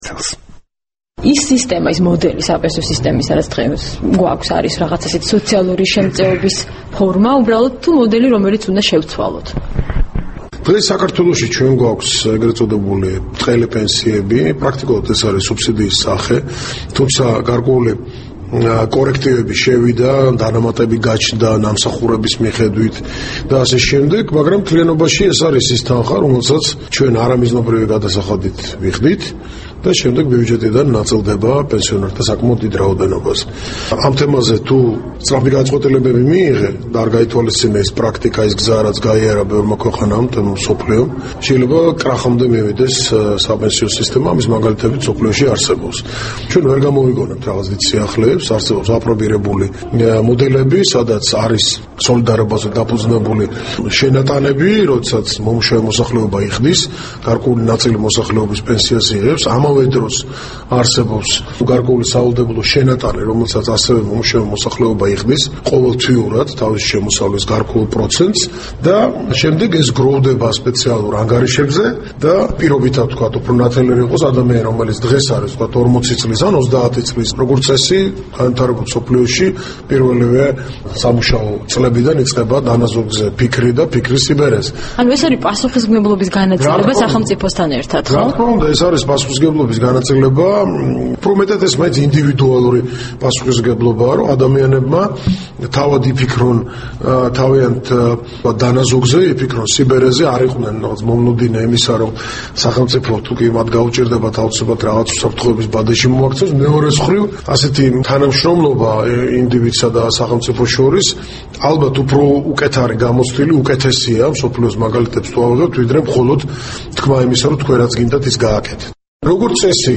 ინტერვიუ გიგი წერეთელთან